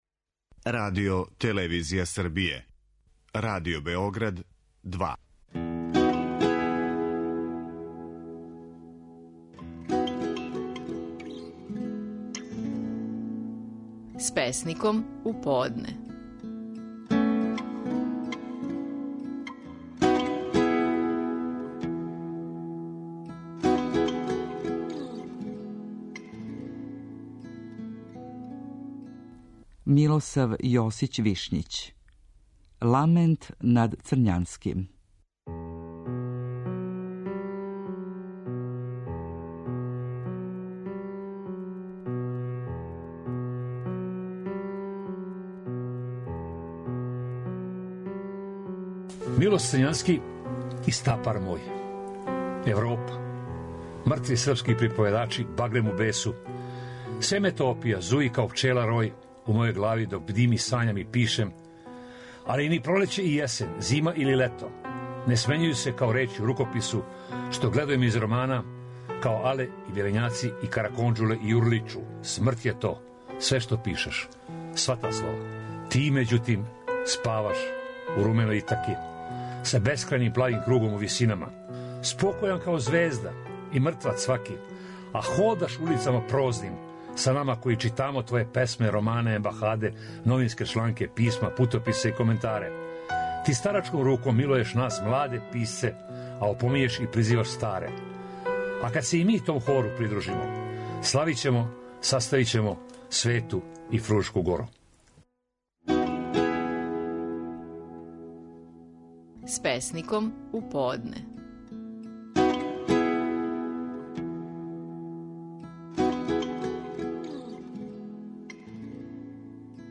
Стихови наших најпознатијих песника, у интерпретацији аутора.
Милосав Јосић Вишњић говори песму „Ламент над Црњанским".